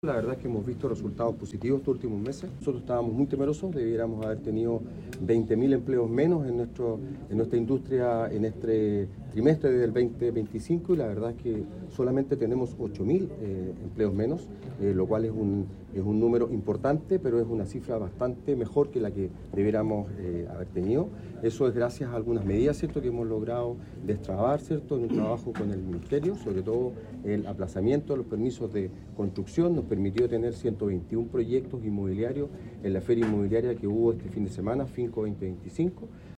La cita tuvo lugar en el auditorio de la Cámara Chilena de la Construcción en Concepción y forma parte del despliegue integral del Gobierno para enfrentar el impacto económico tras el cierre de la Compañía Siderúrgica Huachipato.